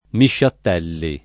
[ miššatt $ lli ]